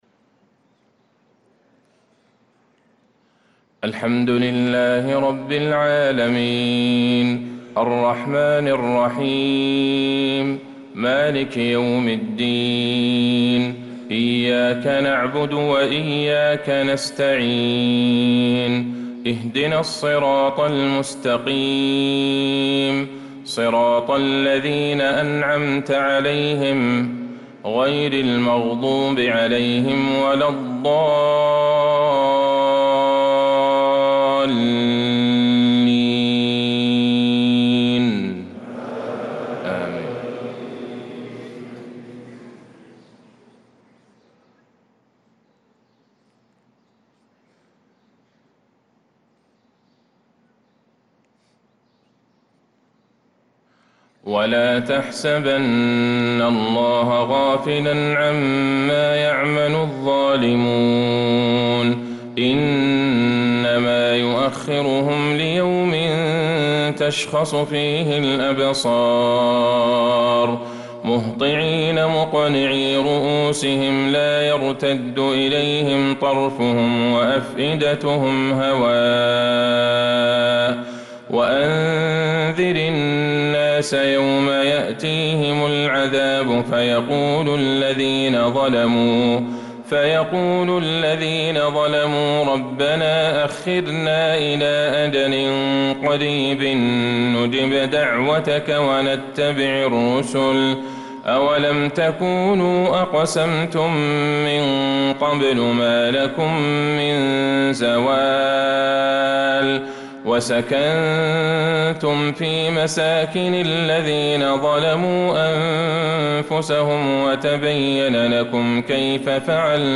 صلاة الفجر للقارئ عبدالله البعيجان 19 شوال 1445 هـ